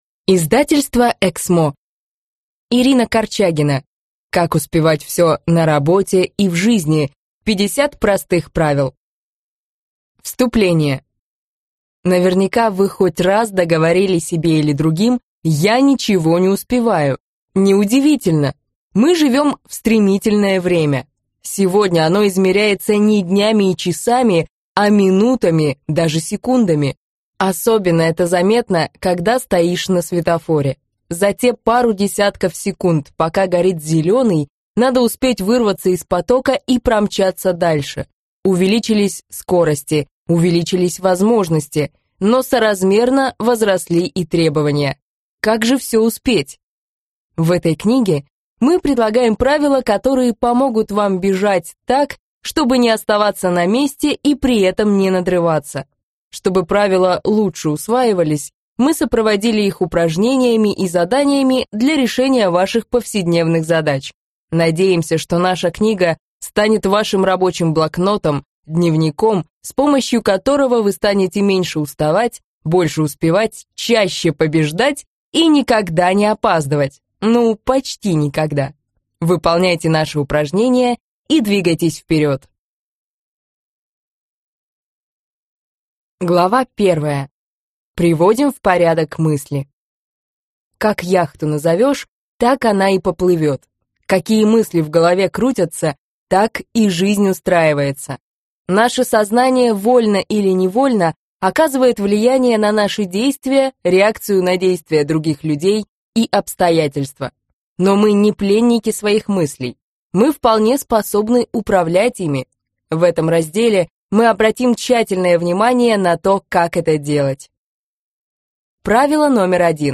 Аудиокнига Как успевать все на работе и в жизни. 50 простых правил | Библиотека аудиокниг